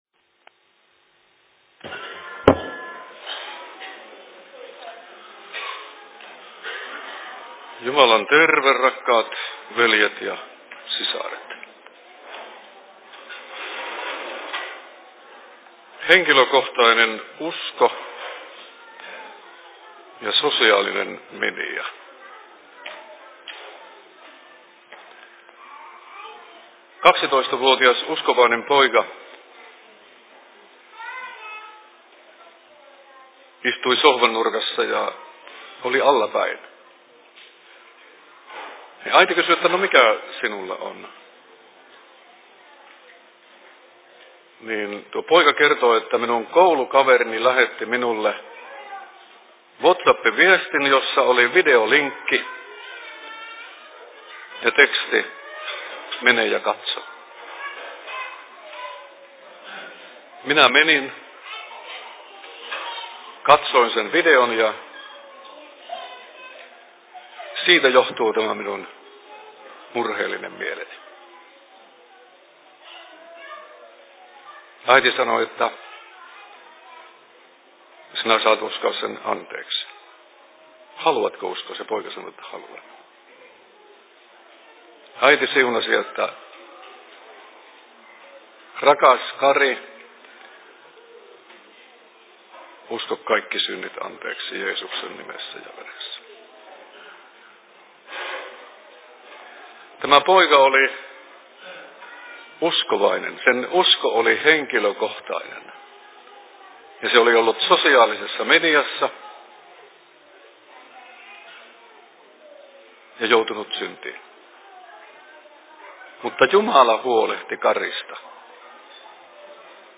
Kevätseurat/Alustus Laukaan RY:llä 11.03.2017 16.21